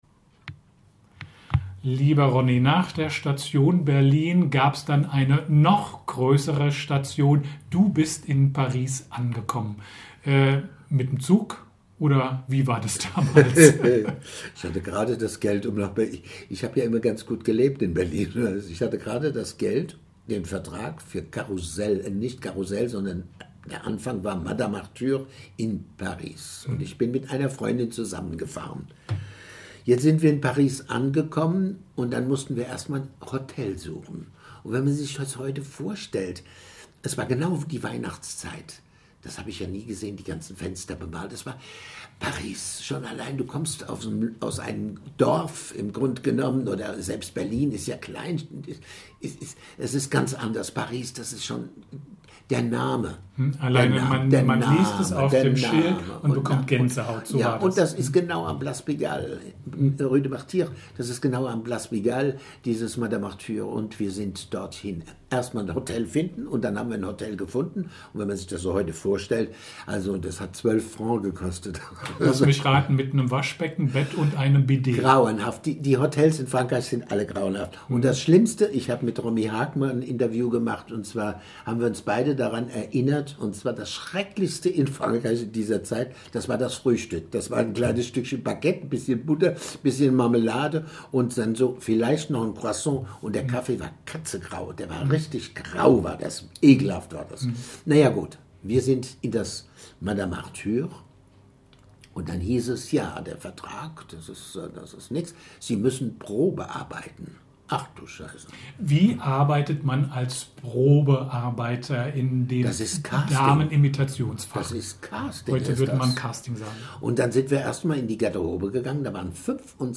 Teil 3 des Interviews